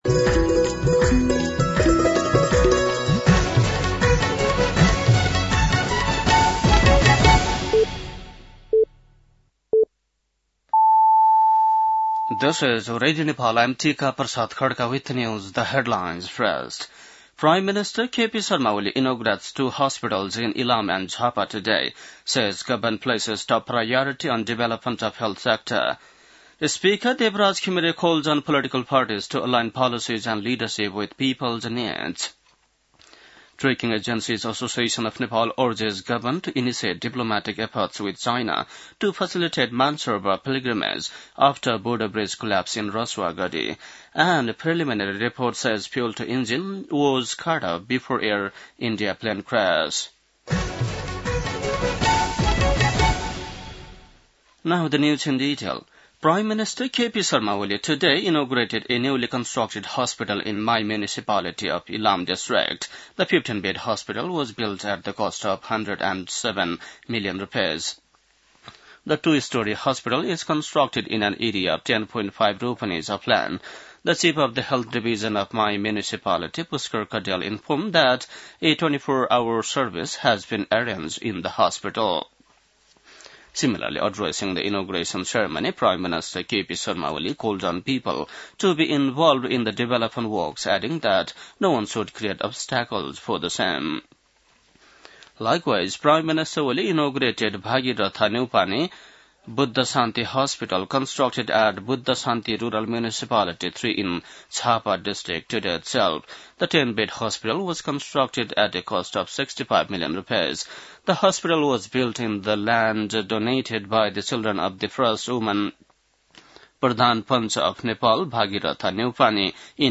बेलुकी ८ बजेको अङ्ग्रेजी समाचार : २८ असार , २०८२